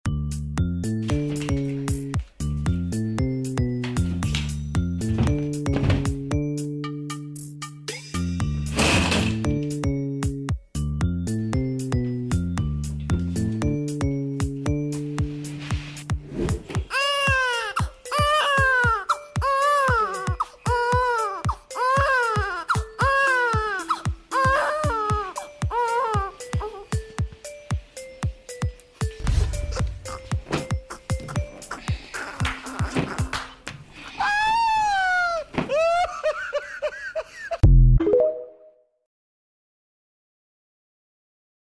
You Just Search Sound Effects And Download. tiktok laughing sound effects Download Sound Effect Home